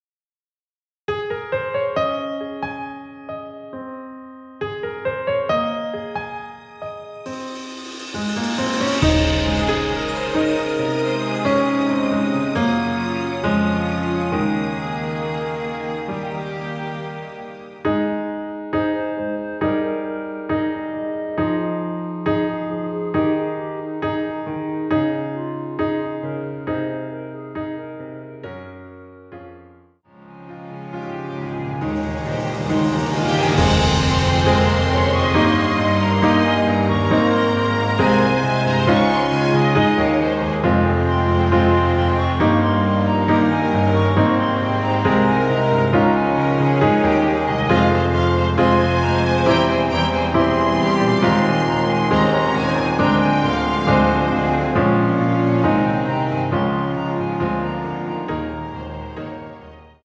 원키에서(-1)내린 멜로디 포함된 MR입니다.(미리듣기 확인)
Ab
앞부분30초, 뒷부분30초씩 편집해서 올려 드리고 있습니다.